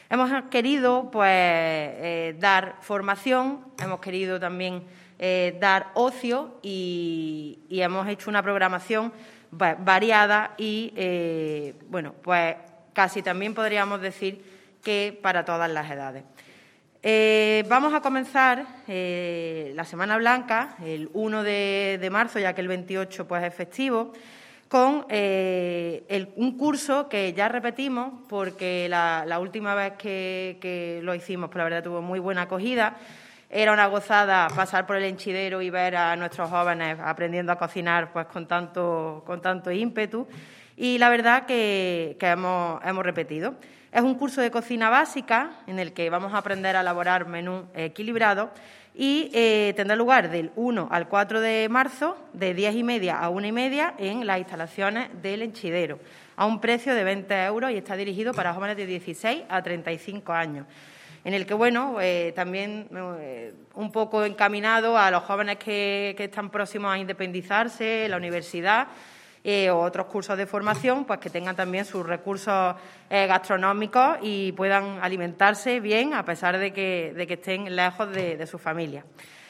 La teniente de alcalde delegada de Juventud, Elena Melero, ha presentado dicha programación en rueda de prensa en la mañana de hoy junto a representantes del colectivo “La Cara B”, promotores de un evento musical al que se dará cabida.
Cortes de voz